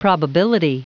Prononciation du mot probability en anglais (fichier audio)